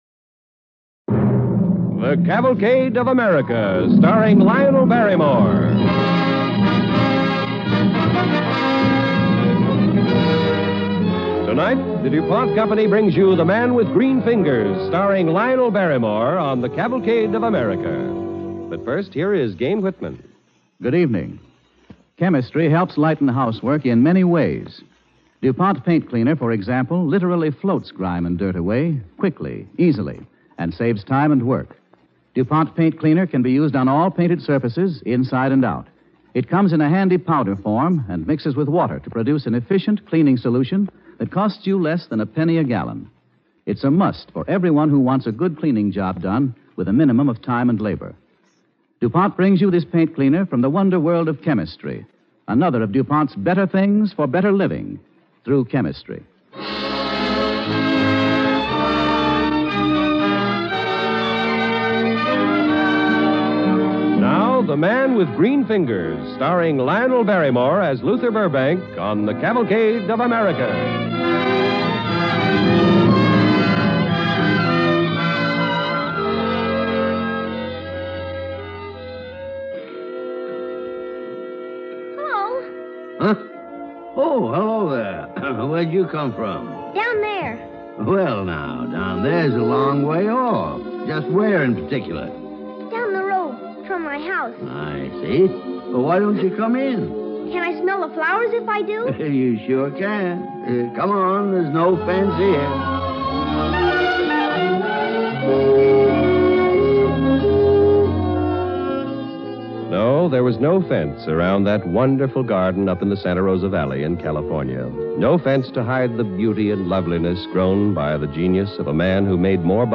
The Man with Green Fingers, starring Lionel Barrymore